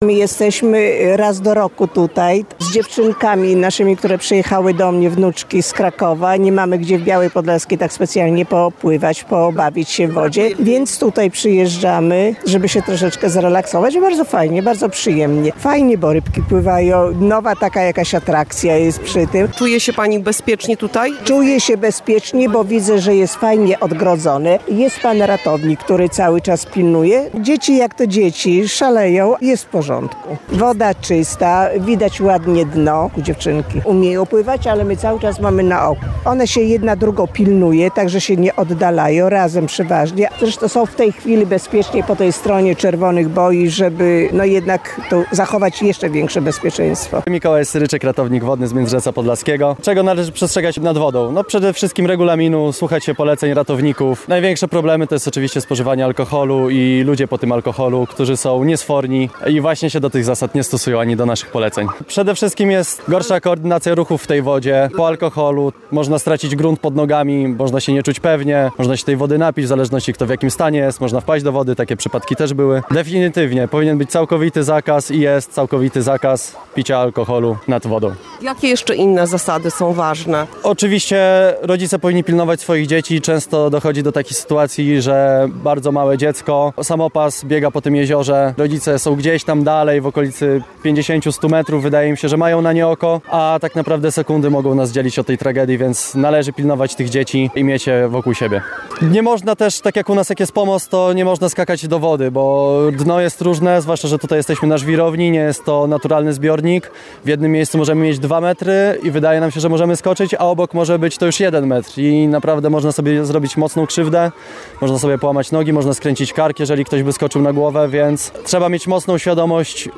Sprawdziliśmy to, odwiedzając Jeziorka Międzyrzeckie, jedyne strzeżone kąpielisko w powiecie bialskim.